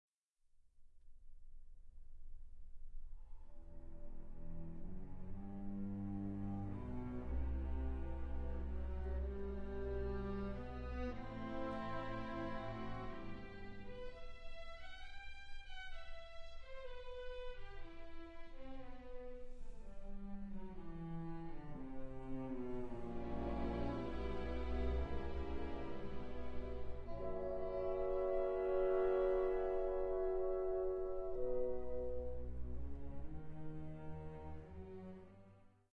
Aria